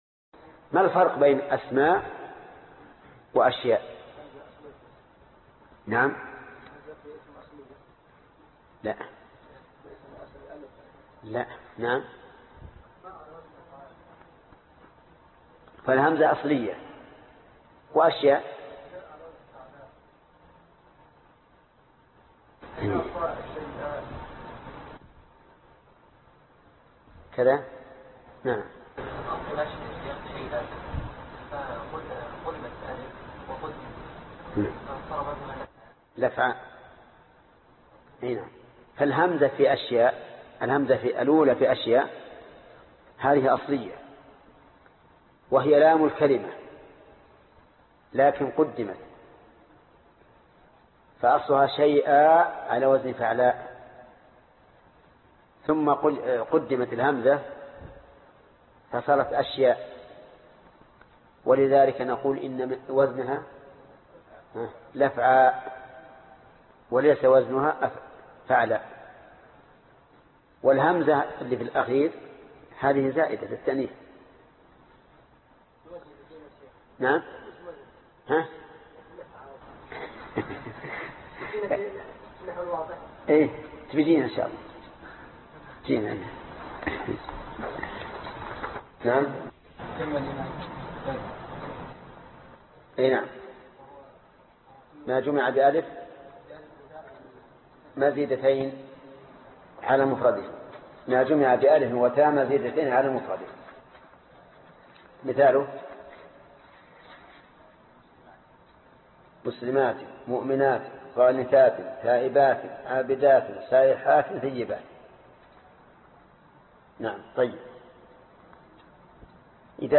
الدرس 34 المعرب والمبنى23- الابيات 42 و 43 (شرح الفية ابن مالك) - فضيلة الشيخ محمد بن صالح العثيمين رحمه الله